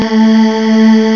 Arr1.wav is a classic sample used all over the place in the 80s, a breathy female clip originally recorded for the Fairlight synthesizer.
For reference the file below is pitched at A2. arr1-48k.wav 331.3K